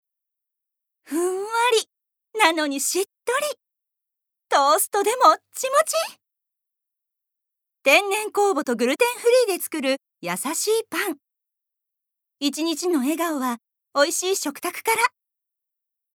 Voice Sample
ナレーション２